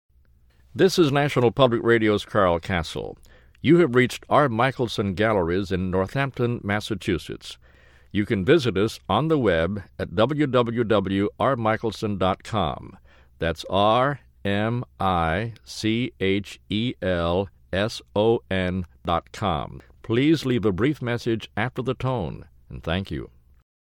Listen to NPR’s Carl Kasell welcoming message on our voicemail.